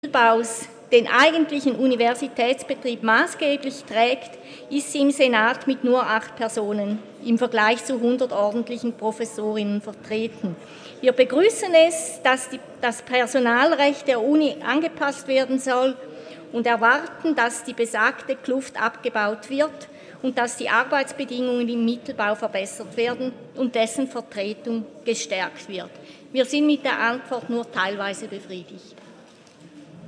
26.11.2019Wortmeldung
Sprecher: Schmid-St.Gallen
Session des Kantonsrates vom 25. bis 27. November 2019